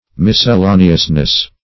miscellaneousness - definition of miscellaneousness - synonyms, pronunciation, spelling from Free Dictionary
miscellaneousness.mp3